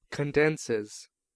Ääntäminen
Ääntäminen US Haettu sana löytyi näillä lähdekielillä: englanti Condenses on sanan condense yksikön kolmannen persoonan indikatiivin preesens.